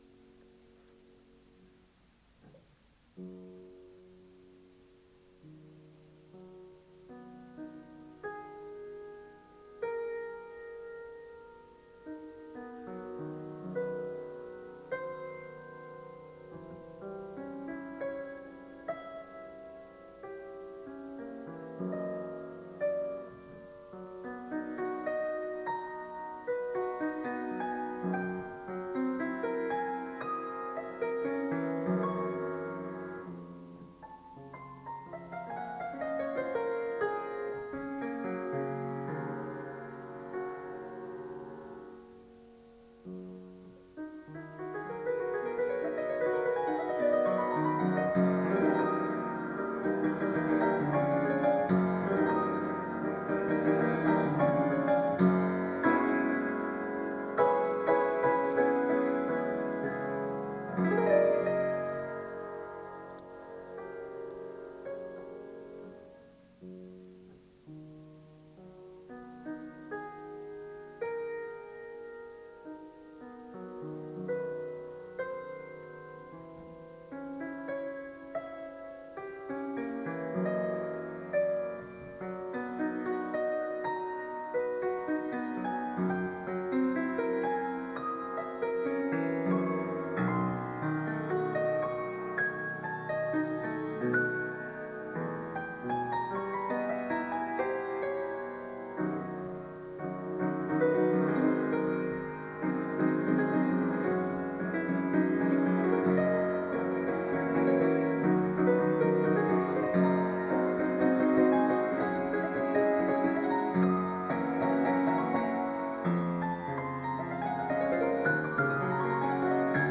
Concert Recording
Durban